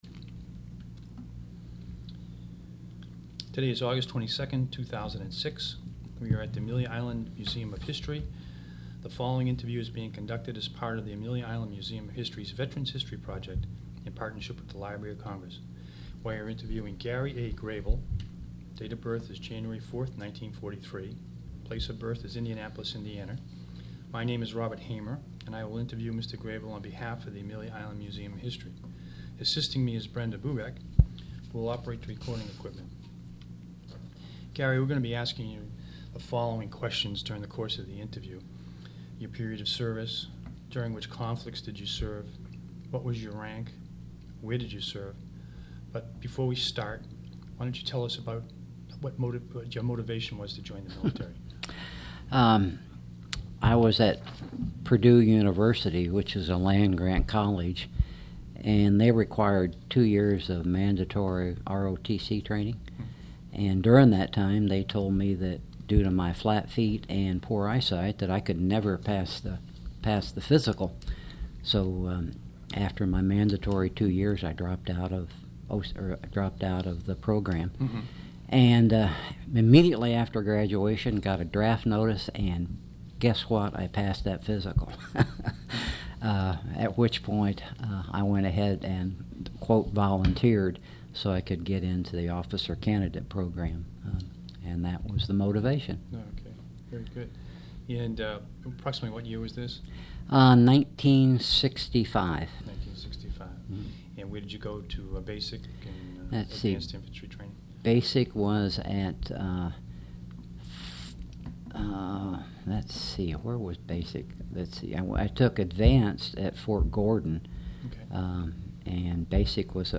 Click Here to play the Oral History Recording.
Interview place AIMH